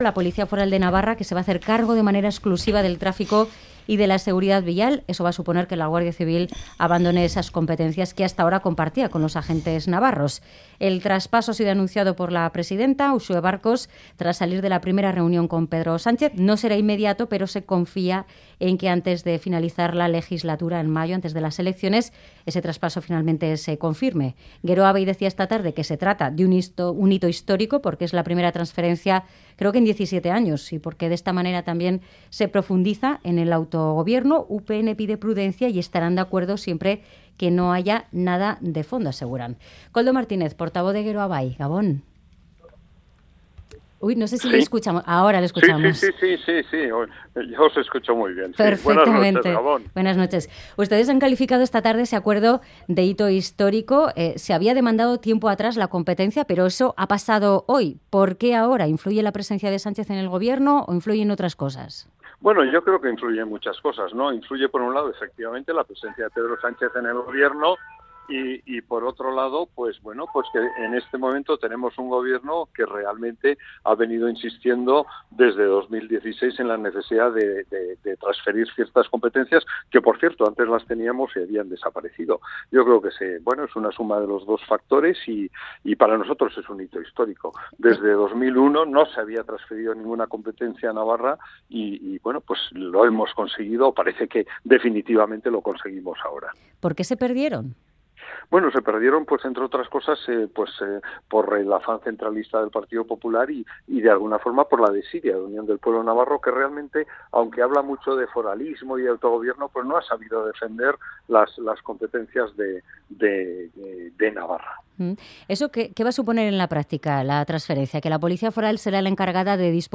Audio: Analizamos con Koldo Martínez, de Geroa Bai, el anuncio hecho por Uxue Barkos del traspaso de las competencias de tráfico y segurdad vial a la Policía Foral